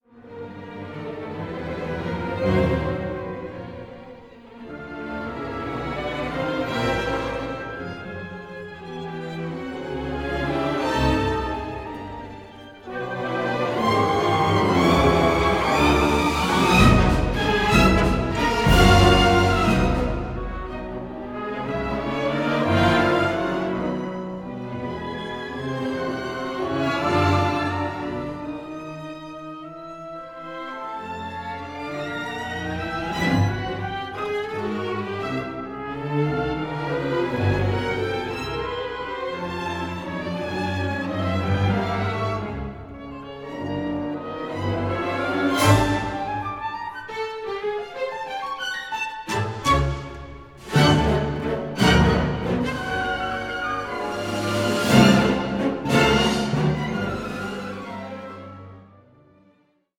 With orchestral textures both radiant and unsettling